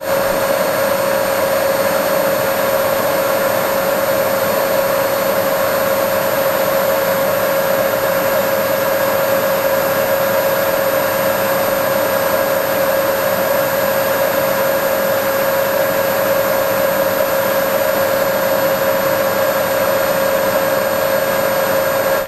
Звуки турбины самолета
Шум работающей авиационной турбины